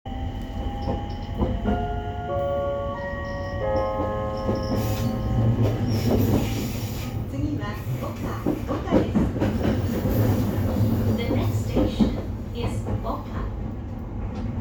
【車内放送】次は岡
車内放送は、冒頭のチャイムが特急型にありがちな4打点を用いているのが特徴。